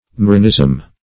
Marinism \Ma*ri"nism\, n.
marinism.mp3